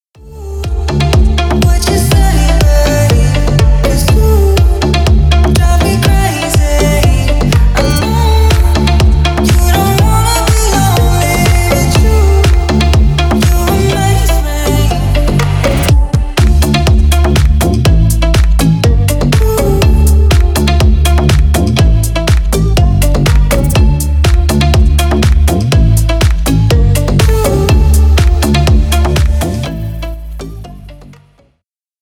Танцевальные
громкие